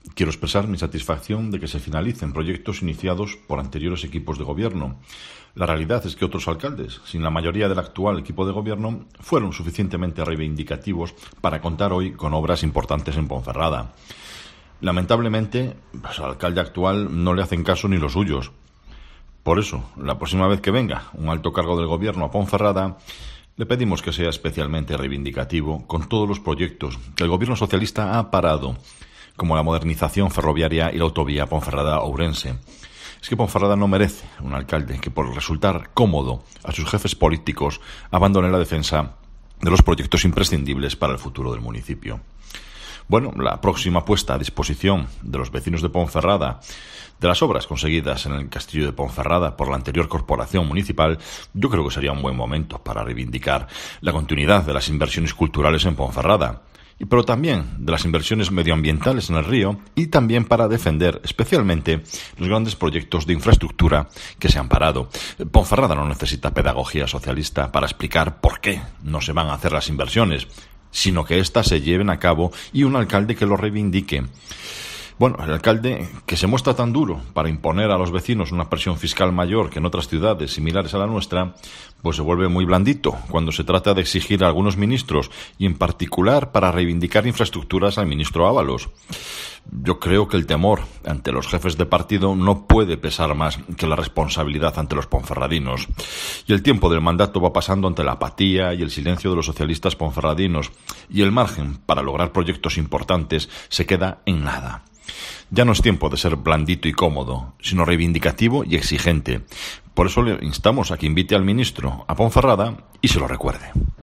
AUDIO: Escucha aquí las declaraciones de Marco Morala, portavoz popular en la capital berciana